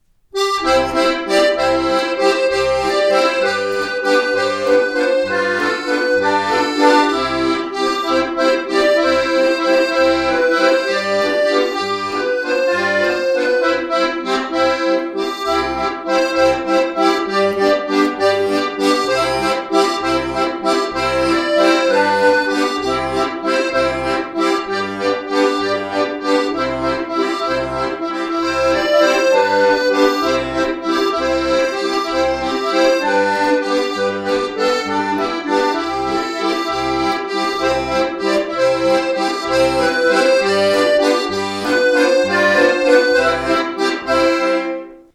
• Inklusive 2. Stimme
• Sehr einfache Standardbass-Begleitung